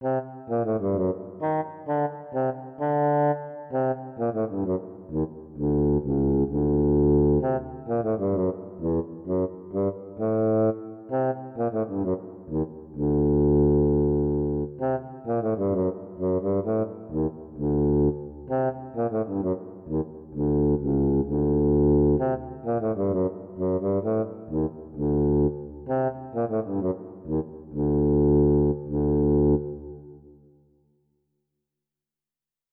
Música ambiental del cuento: La princesa Ranita
ambiente
melodía
sintonía